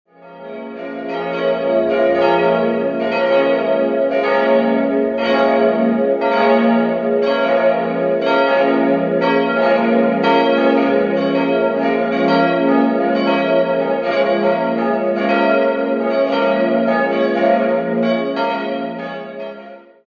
Im Inneren vereinen sich protestantische Strenge und Schlichtheit mit barocker Raumarchitektur. Idealquartett: f'-as'-b'-des'' Die Glocken wurden 1954 vom Bochumer Verein für Gussstahlfabrikation gegossen.